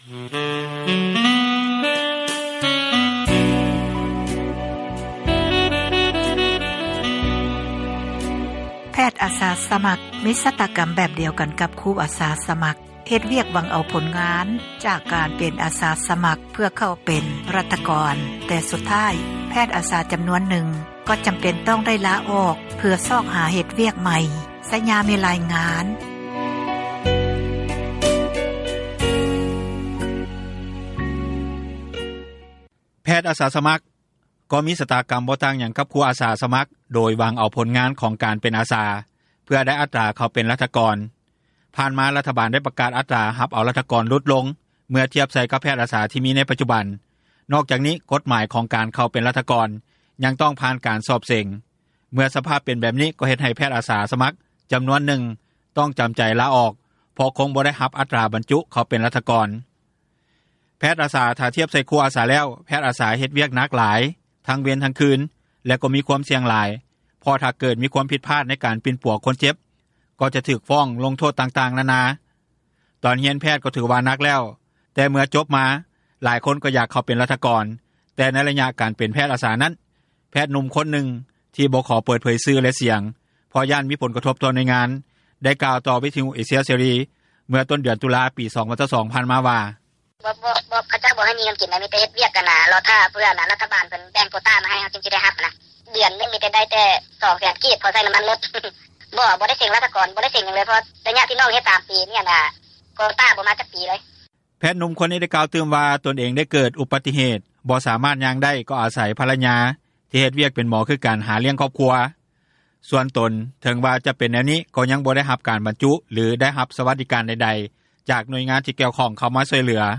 ນັກຂ່າວ ພົລເມືອງ